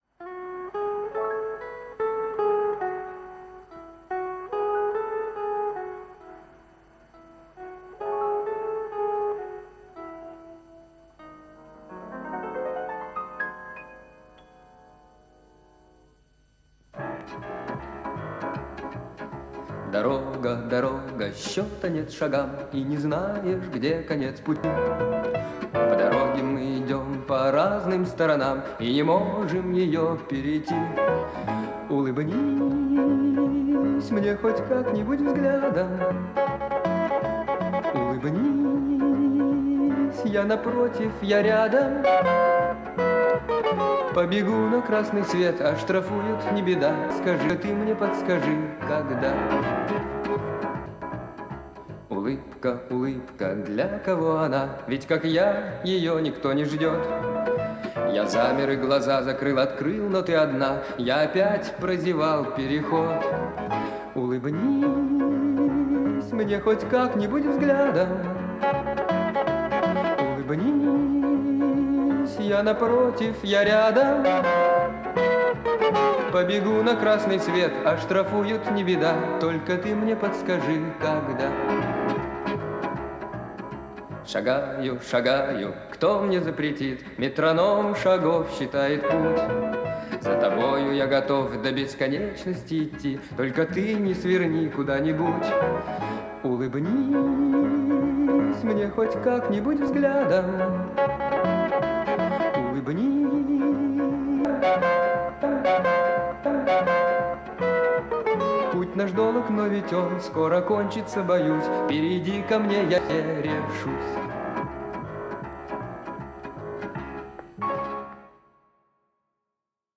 Скажу сразу - в подборке качество очень различное.